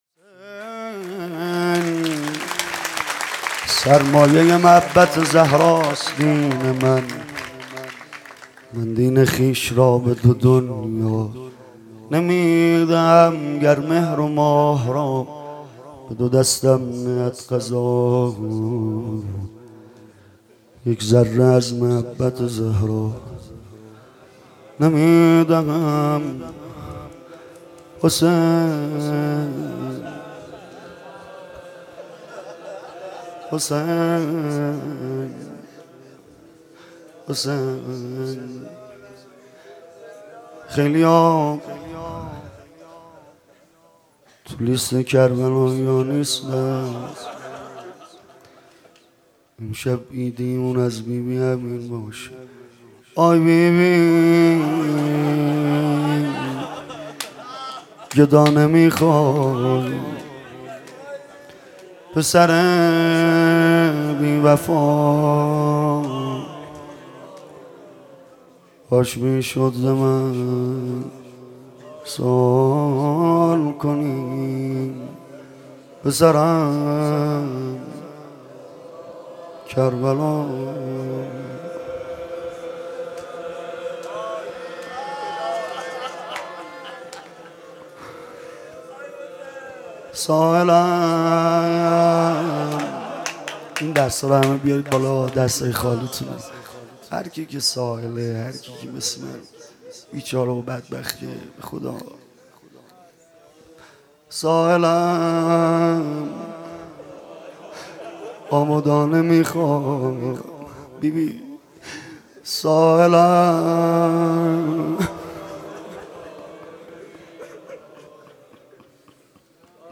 با مدیحه سرایی توسط
سرود